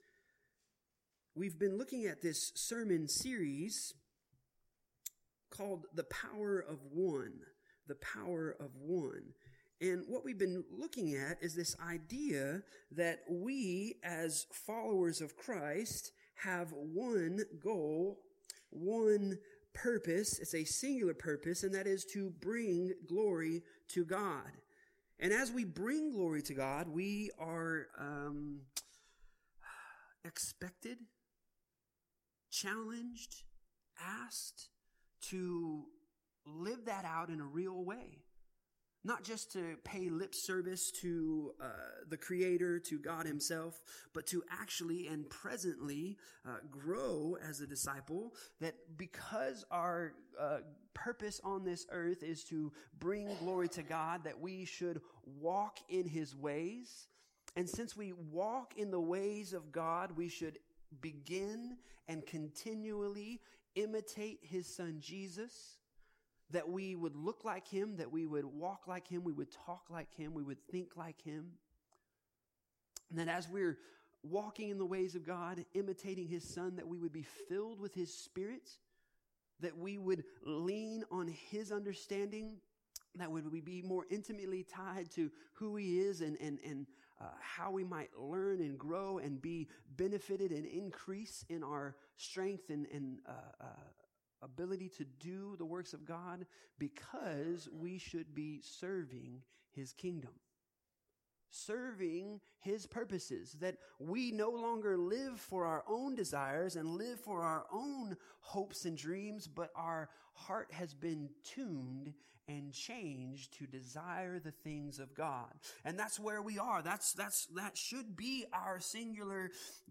The audio recording is also from the in-person gathering – recorded live through our church sound system.